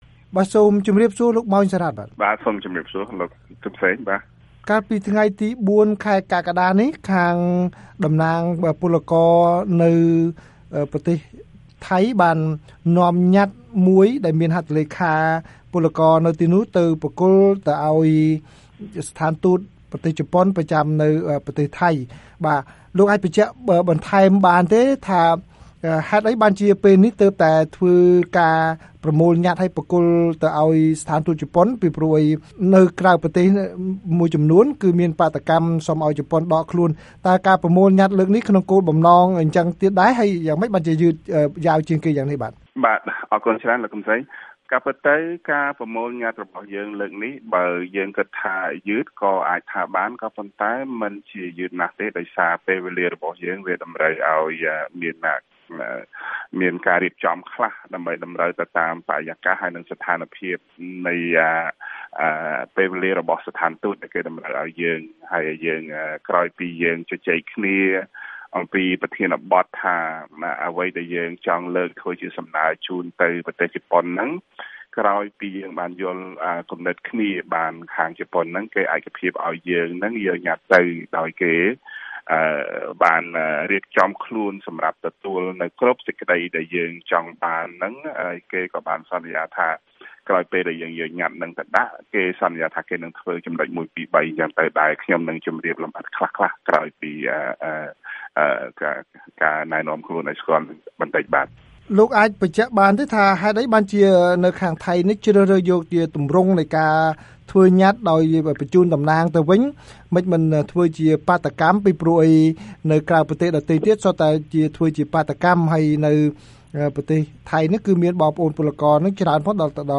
បទសម្ភាសន៍VOA៖ ពលករនៅថៃដាក់ញត្តិទាមទារជប៉ុនបញ្ឈប់ការគាំទ្រការបោះឆ្នោត